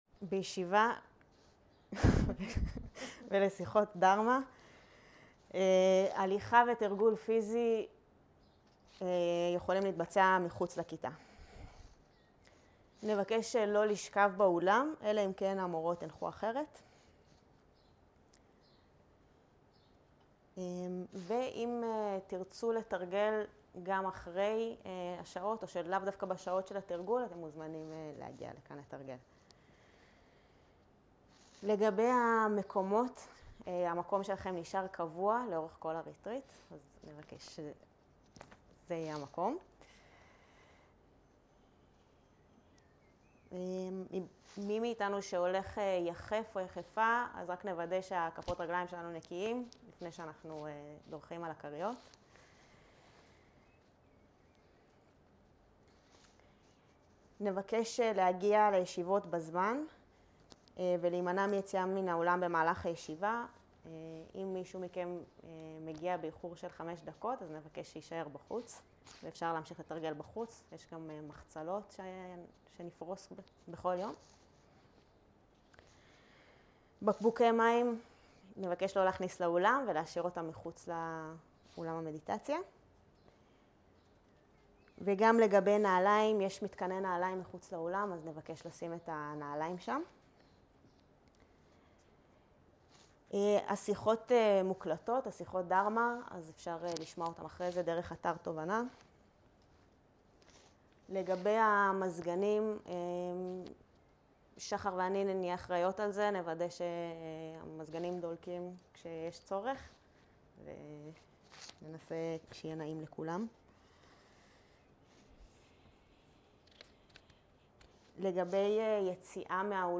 Dharma type: Opening talk שפת ההקלטה